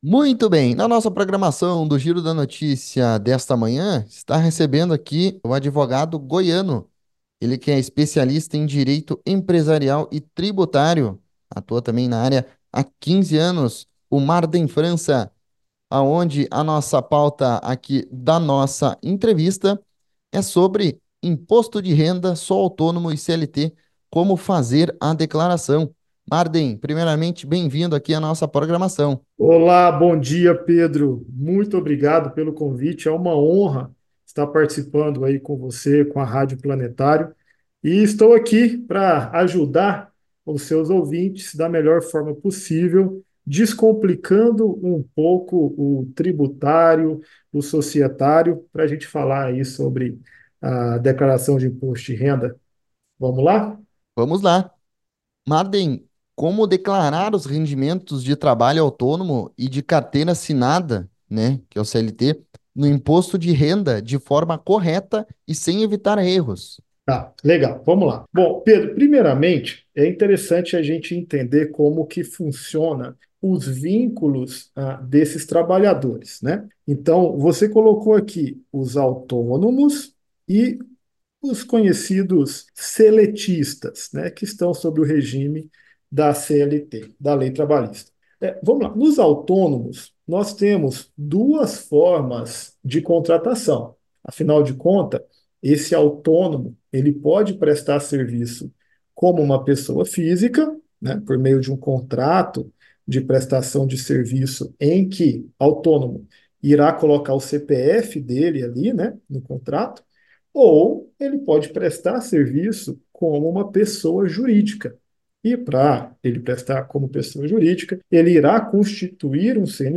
Entrevista com Advogado Especialista em Direito Empresarial e Tributário